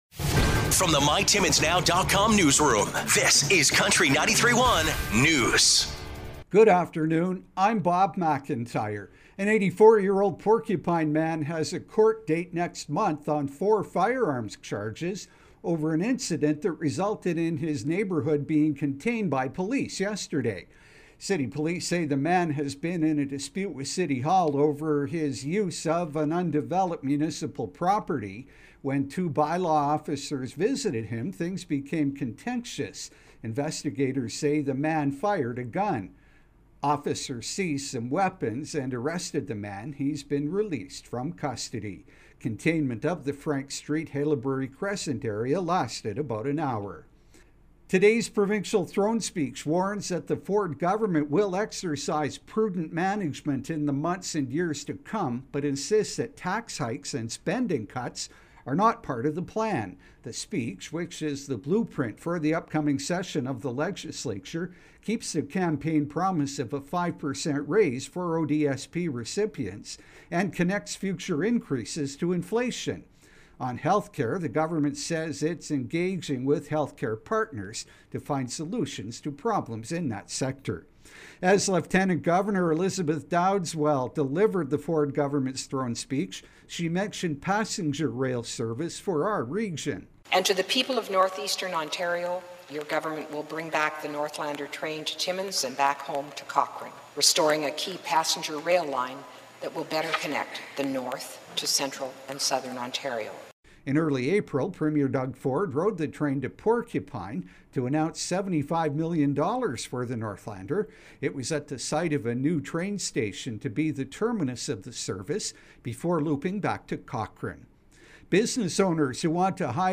5:00pm Country 93.1 News – Tues., Aug. 9, 2022